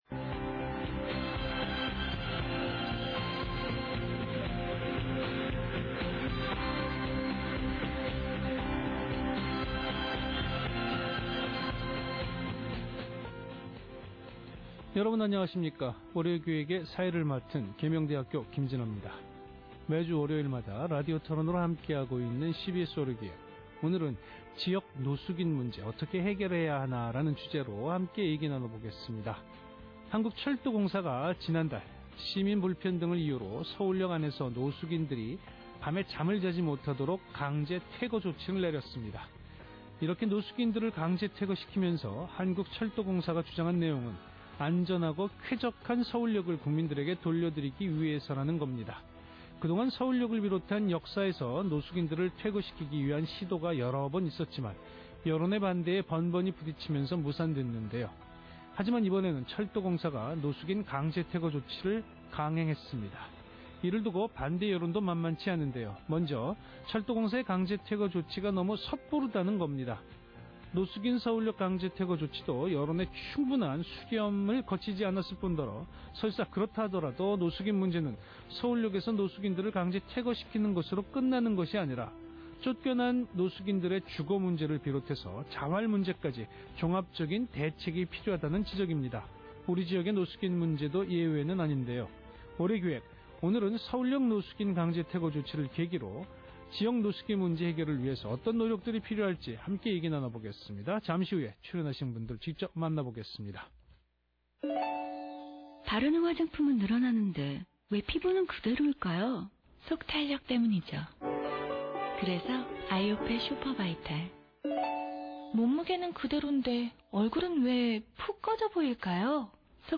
2011년 - 대구cbs방송 [월요기획 라디오 토론회] "노숙인 문제, 어떻게 해결해야 하나?" 서울역 노숙인 강제퇴거 조치에 따른 대구지역 노숙인(홈리스)문제와 대안에 대한 라디오 토론내용입니다.